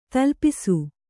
♪ talpisu